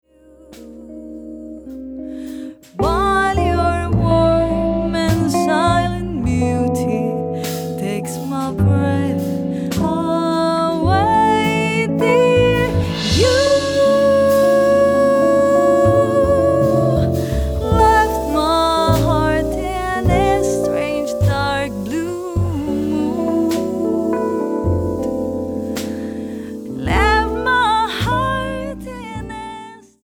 vocals
guitar
piano/Fender Rhodes
bass
drums